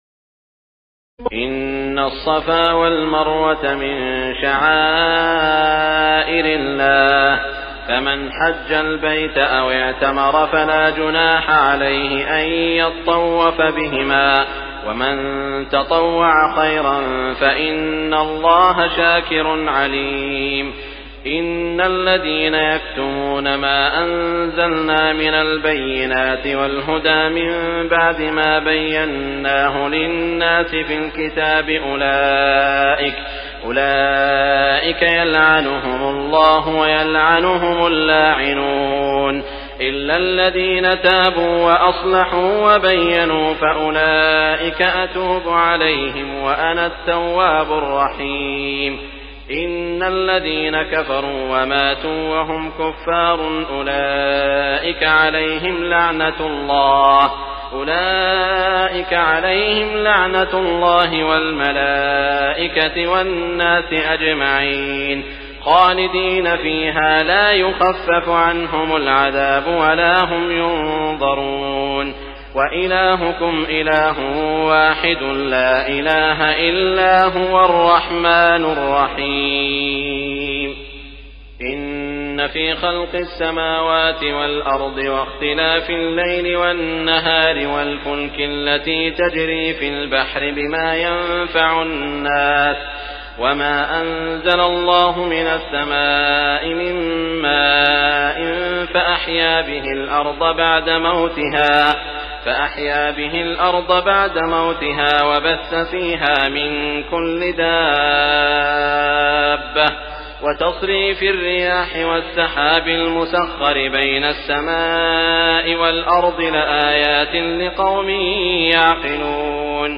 تراويح الليلة الثالثة رمضان 1418هـ من سورة البقرة (158-224) Taraweeh 3st night Ramadan 1418H from Surah Al-Baqara > تراويح الحرم المكي عام 1418 🕋 > التراويح - تلاوات الحرمين